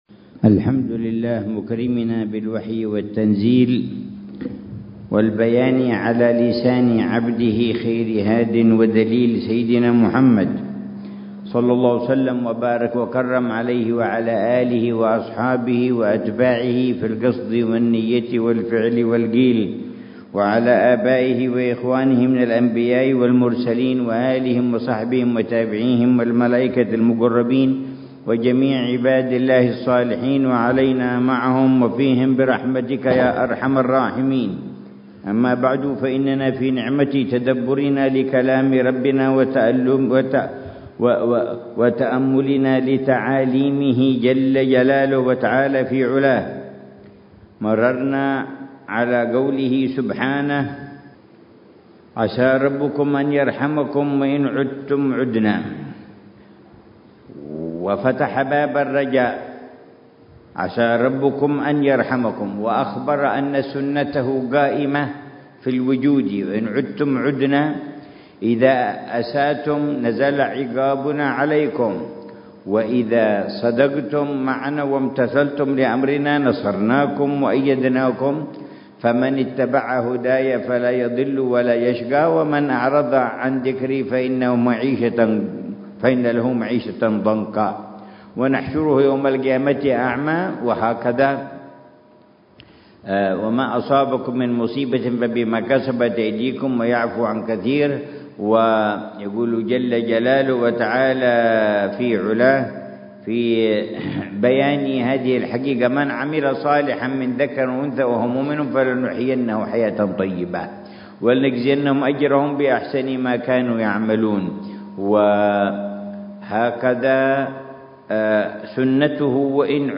تفسير فضيلة العلامة الحبيب عمر بن محمد بن حفيظ للآيات الكريمة من سورة الإسراء: